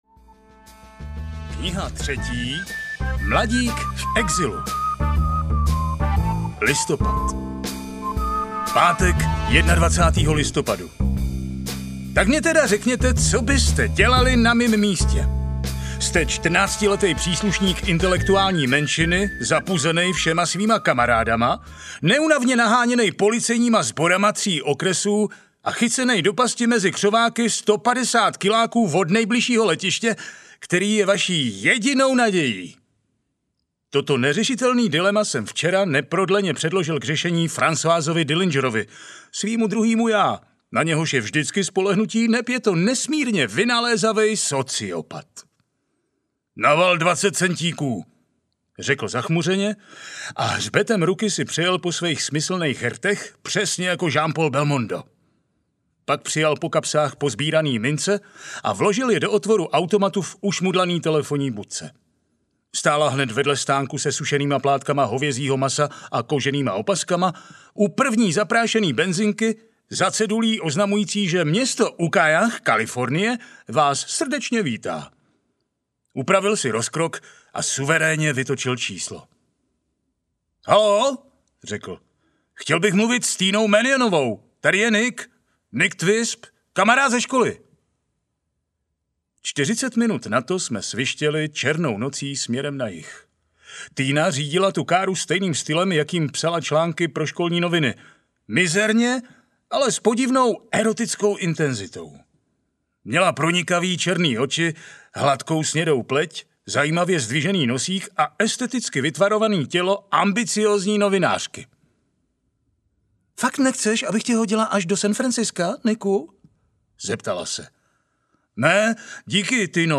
Mládí v hajzlu 3 audiokniha
Ukázka z knihy